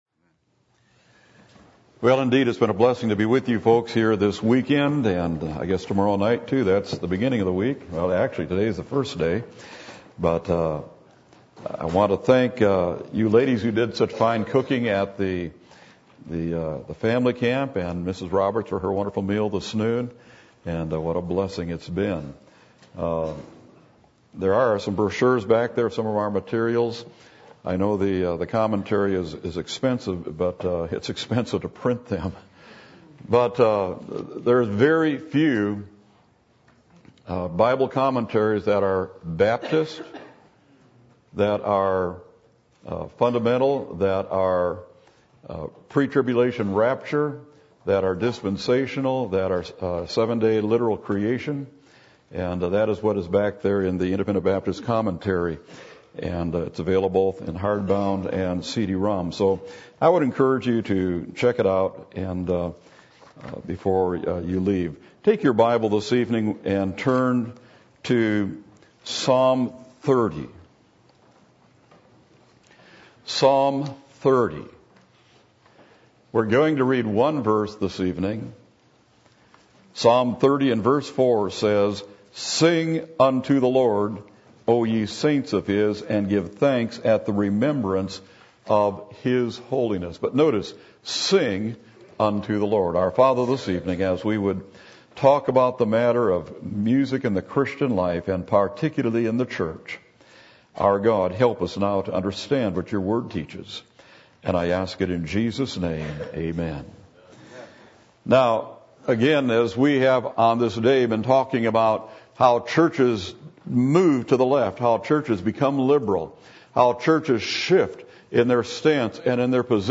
Passage: Psalm 30:4 Service Type: Sunday Evening